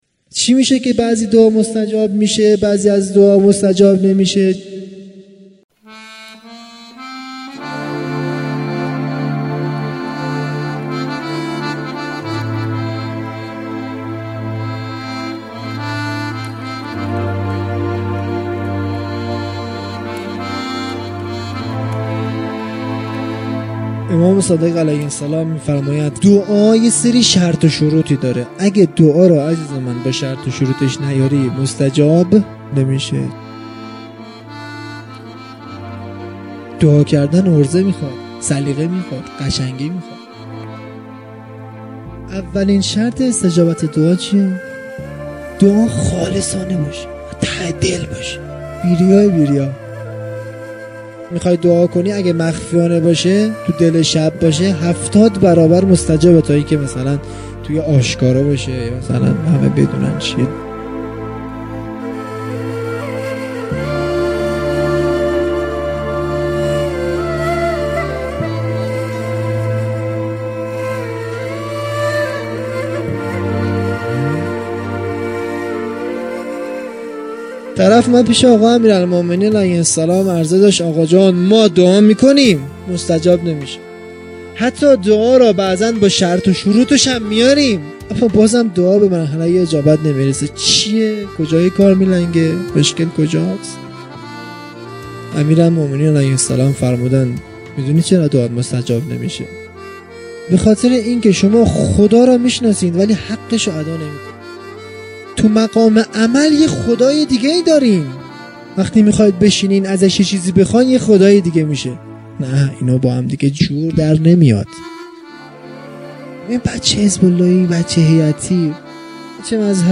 جلسۀ هفتگی (به مناسبت ولادت حضرت معصومه(س)) | 13 تیر 1398